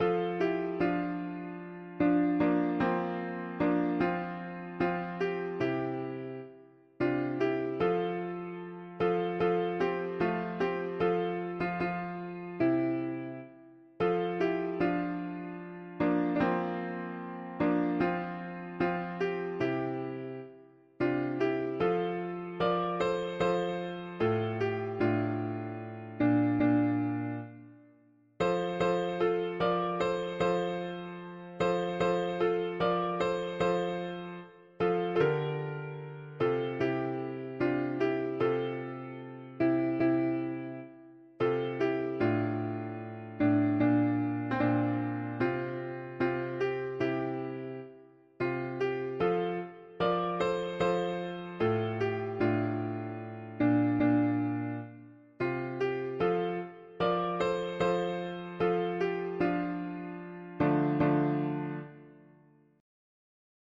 Music: Traditional Irish
Key: F major Meter: 8.8.8.8